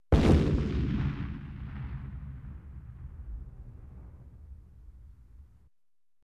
explode1.mp3